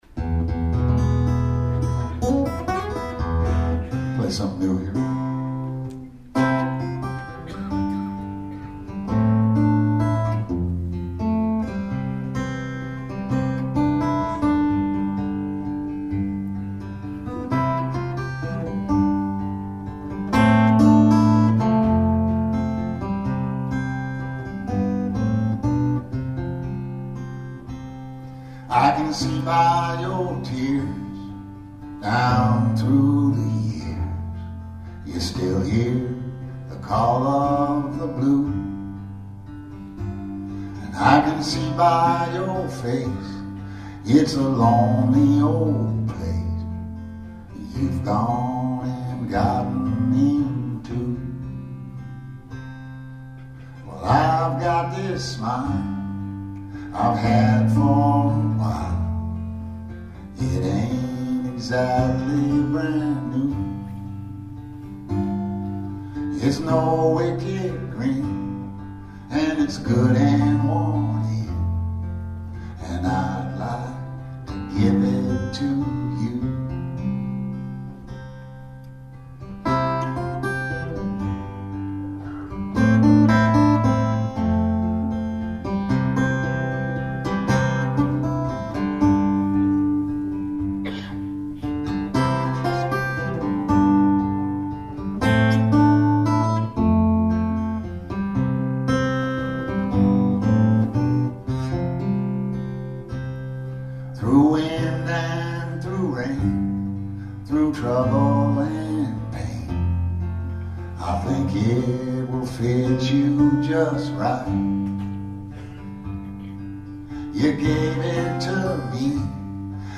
Bloomington, IL USA